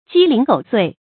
成語注音 ㄐㄧ ㄌㄧㄥˊ ㄍㄡˇ ㄙㄨㄟˋ
成語拼音 jī líng gǒu suì
雞零狗碎發音
成語正音 碎，不能讀作“cuì”。